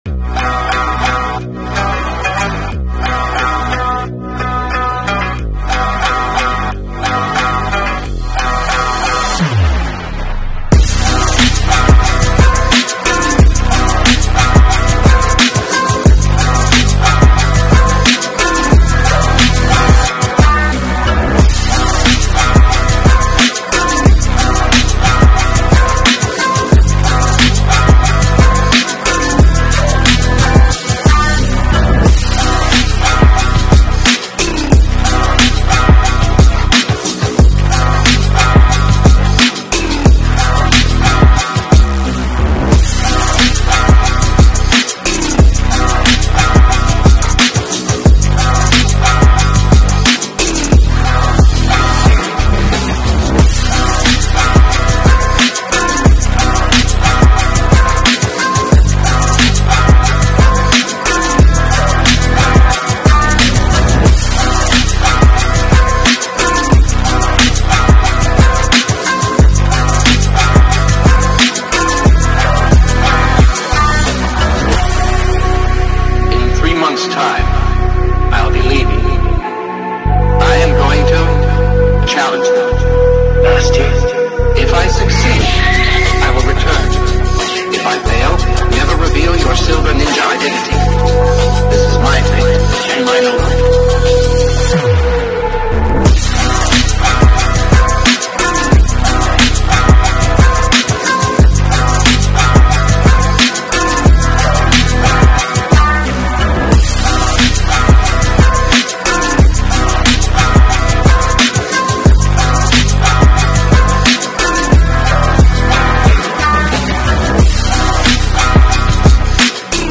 void-ambient-loop.m4a